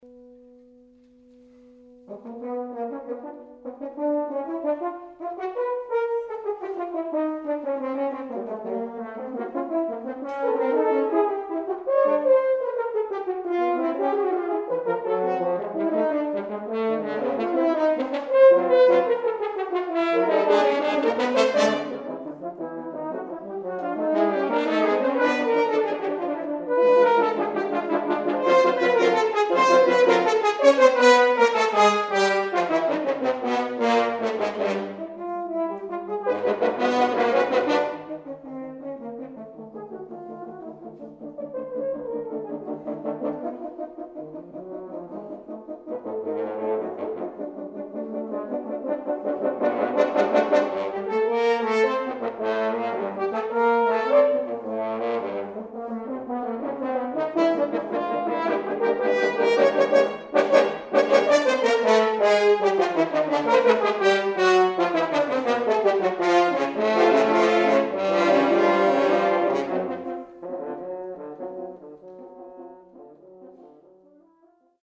For 8 horns